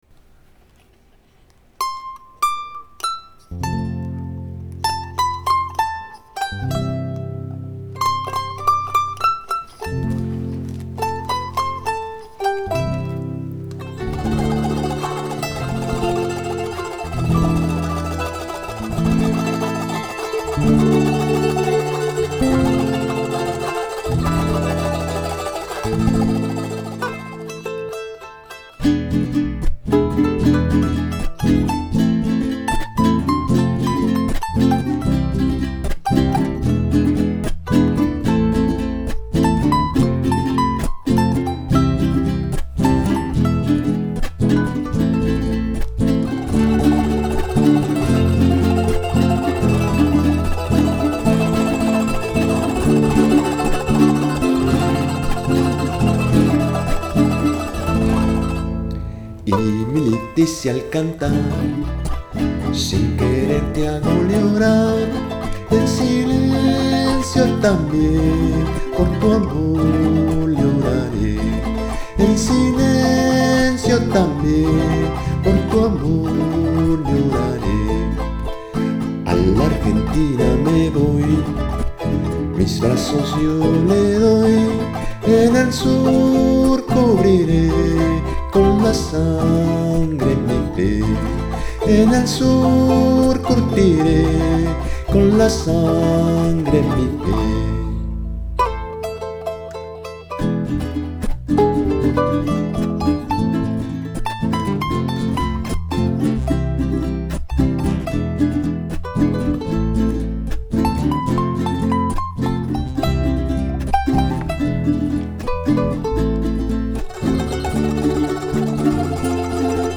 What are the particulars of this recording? Recorded and mixed at the Virú room by yours truly – September-December 2010. College Station, TX